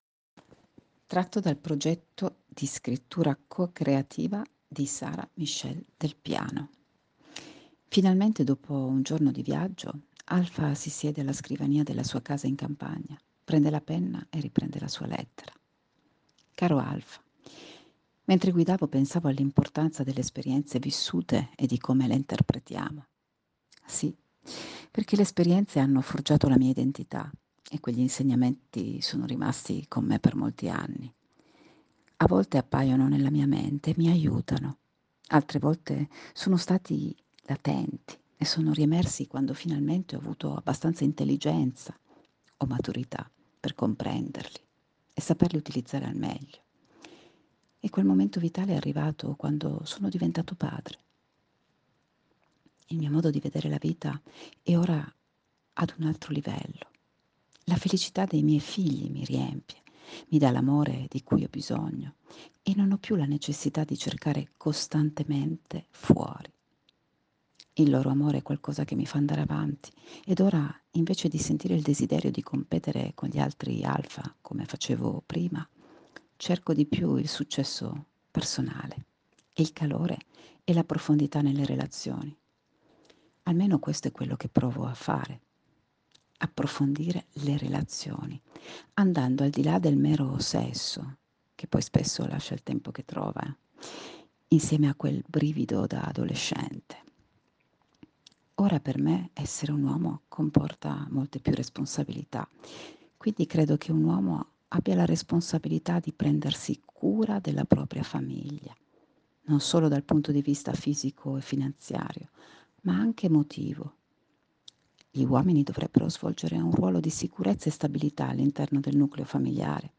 Voce Narrante in Italiano